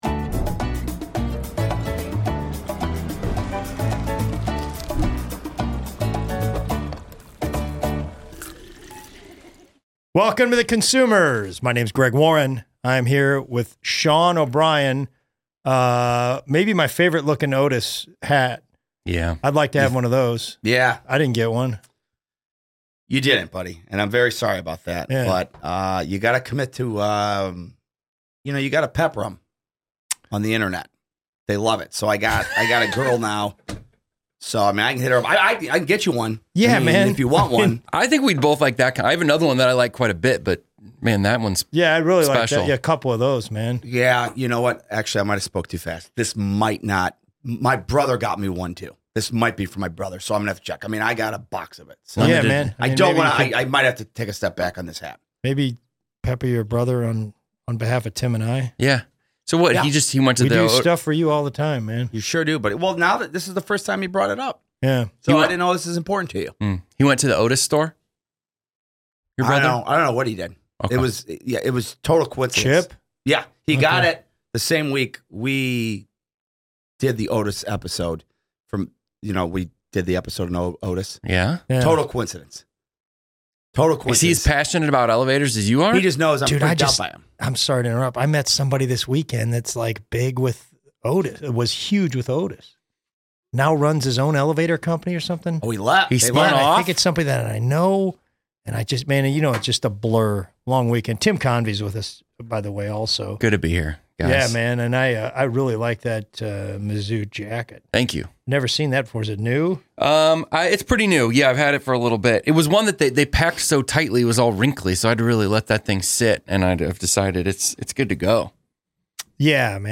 The Consumers · E56 Gillette | Ep. 56 The Consumers Play episode October 14 1h 31m Bookmarks View Transcript Episode Description Another clean, close episode this week as the guys discuss all things Gillette.